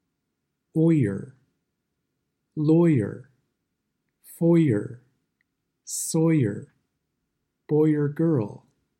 Lesson 5 – “R” vowels /ɝ/, /ɚ/, /ɑr/, /ɜr/, /ɪr/, /ɔr/ – American English Pronunciation